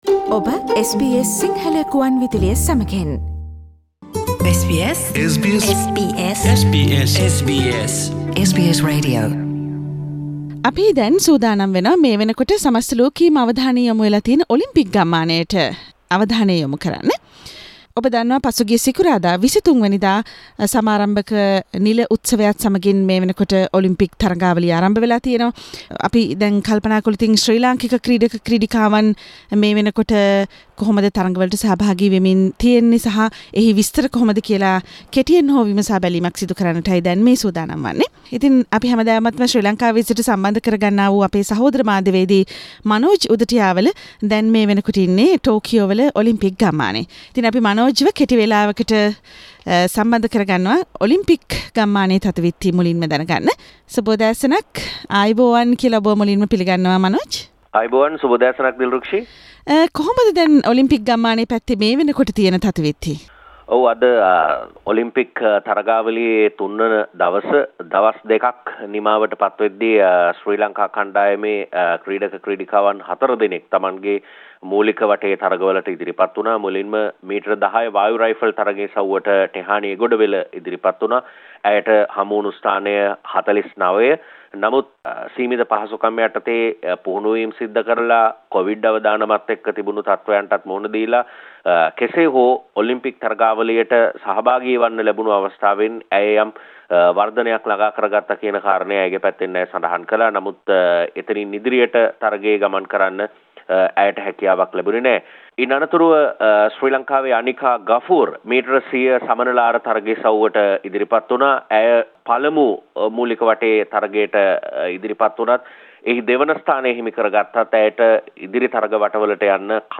ටෝකියෝ ඔලිම්පික් වල ශ්‍රී ලංකික ක්‍රීඩක ක්‍රීඩිකාවන්ගේ තරඟ තොරතුරු ඔලිම්පික් ගම්මානයේ සිට SBS සිංහල සේවයෙන්